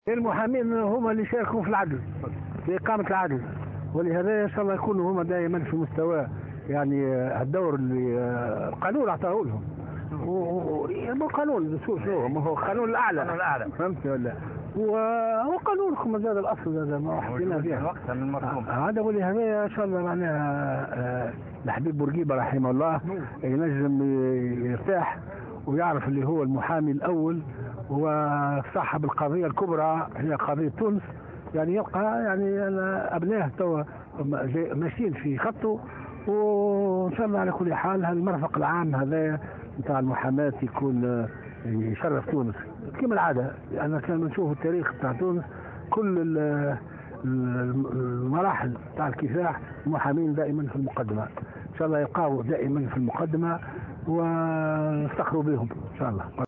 ونوّه رئيس الجمهورية في تصريح لمراسلة "الجوهرة أف أم" بالدور الهام الذي لعبه المحامون في تاريخ الكفاح الوطني وعلى رأسهم المحامي الحبيب بورقيبة.